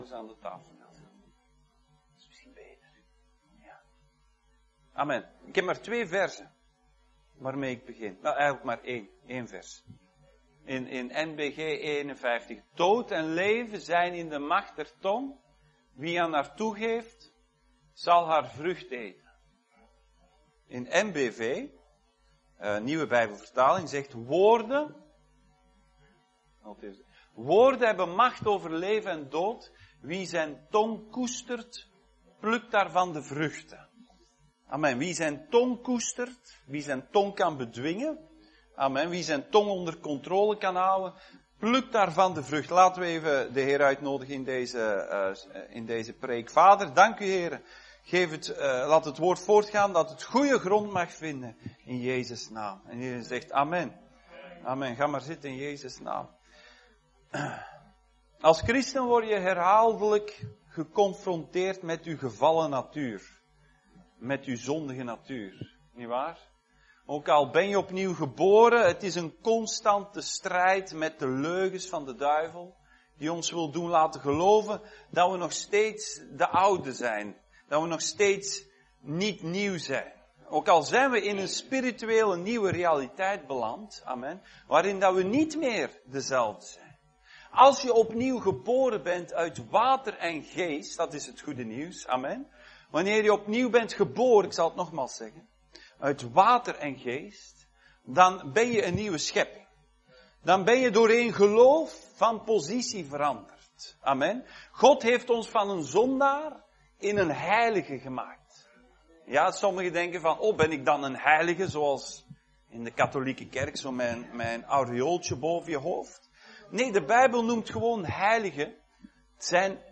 2024 Dienstsoort: Zondag Dienst « Jezus